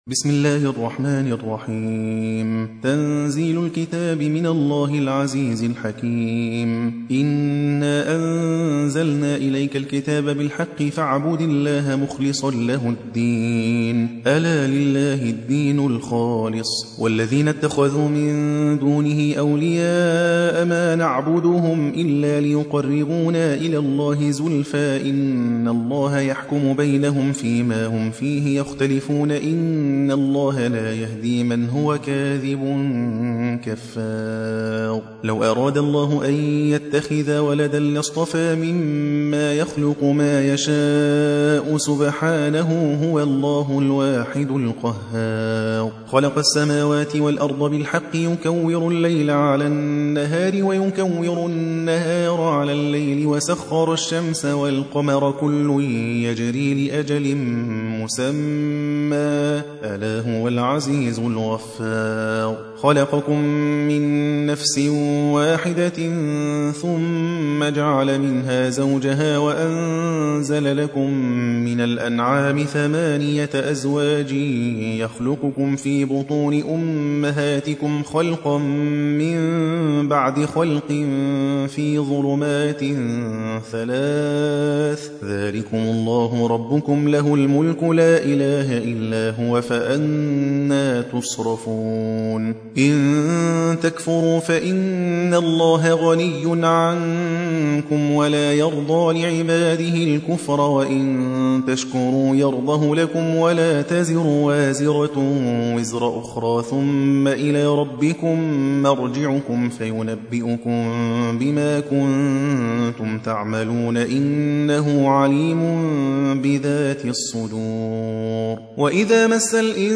39. سورة الزمر / القارئ